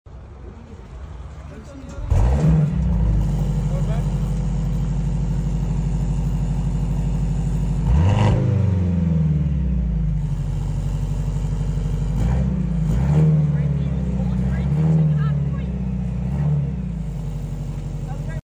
Exhaust and start up of sound effects free download
Exhaust and start up of the new Mercedes-Benz C63 S E-Performance.